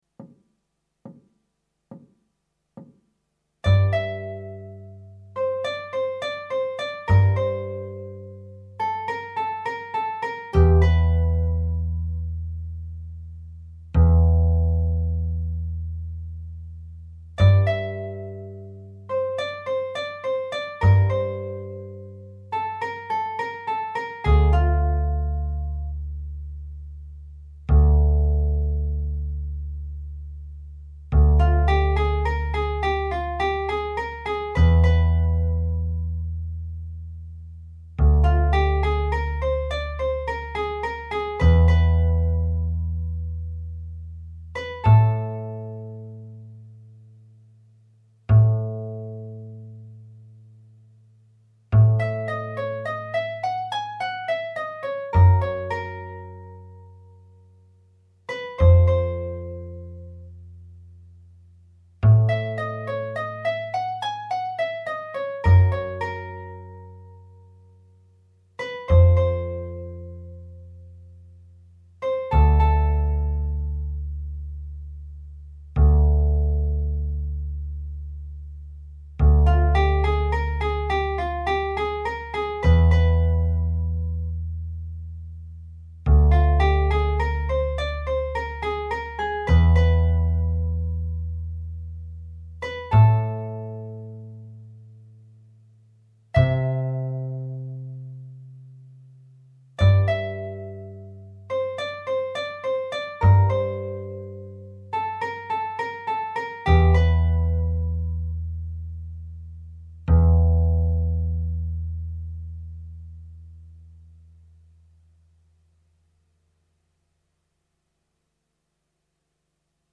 minus Guitar 2